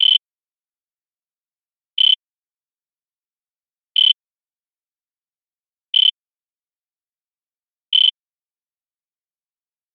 Home > Sound Effect > Alarms
Radiation Meter
Radiation_Meter.mp3